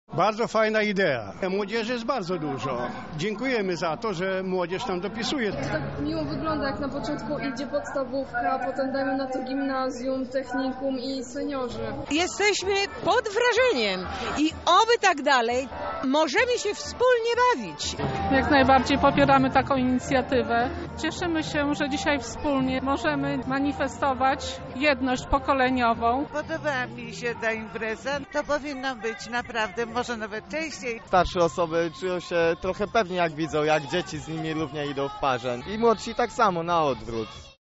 Uczestnicy przemarszu podkreślają znaczenie tego wydarzenia